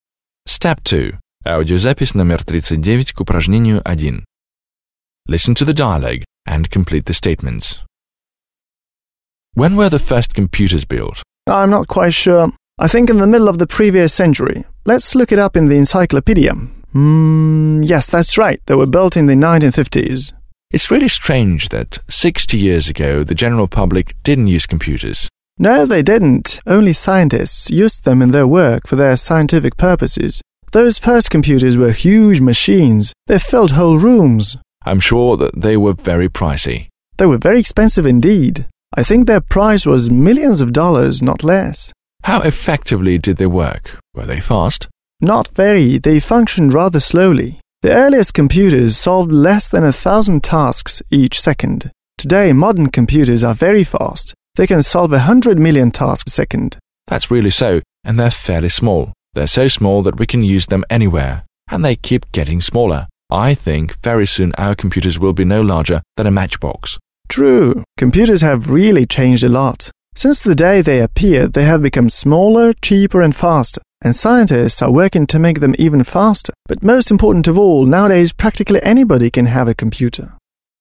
1. You will hear two friends speaking about computers.